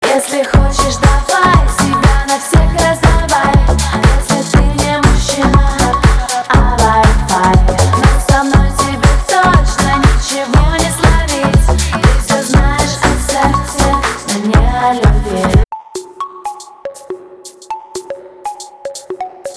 поп
Electronic